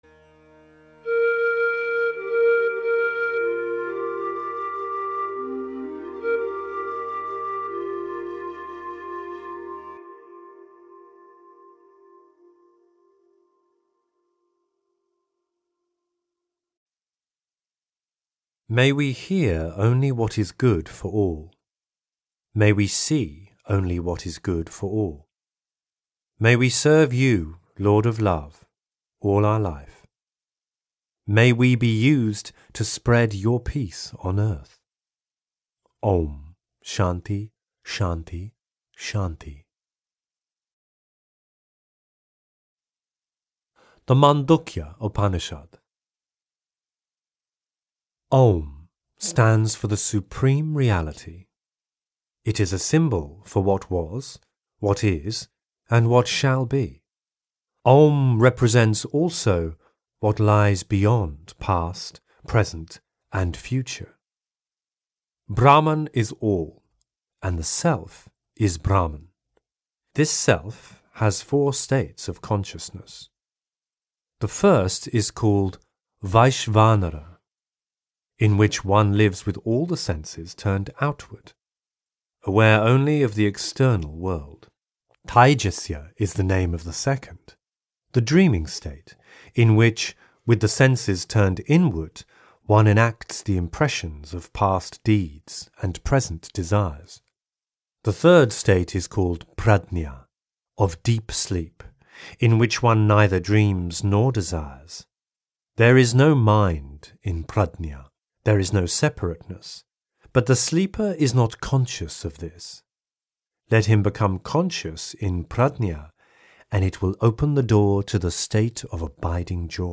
Read by Eknath Easwaran (The Upanishads)